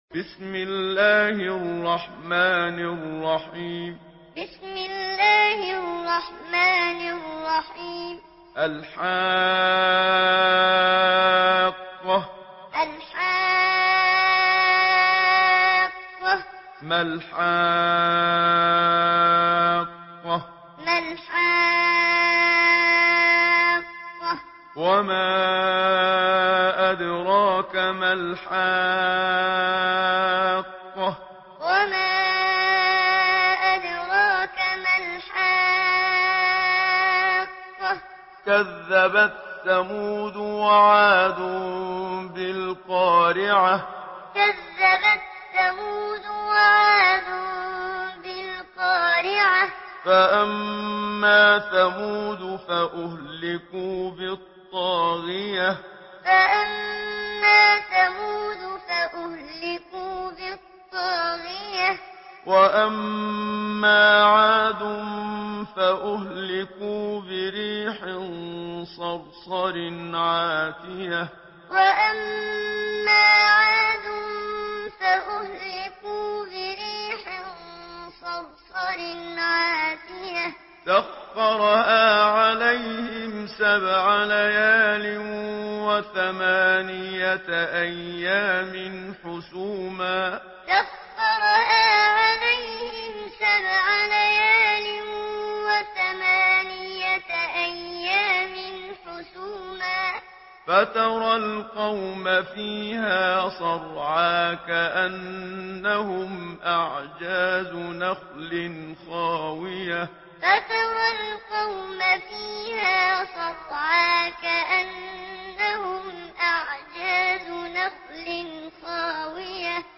Surah Al-Haqqah MP3 in the Voice of Muhammad Siddiq Minshawi Muallim in Hafs Narration
Listen and download the full recitation in MP3 format via direct and fast links in multiple qualities to your mobile phone.